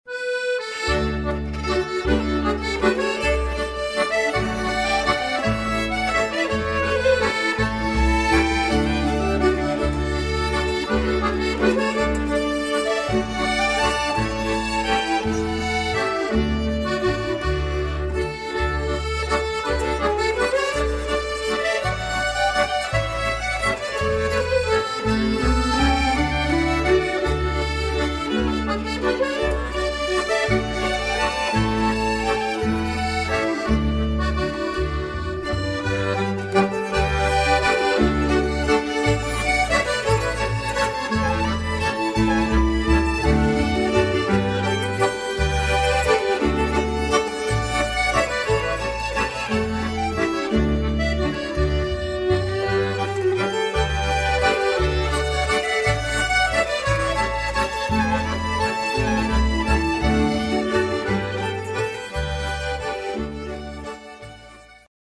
contrabass
accordion
nyckelharpa
chord zither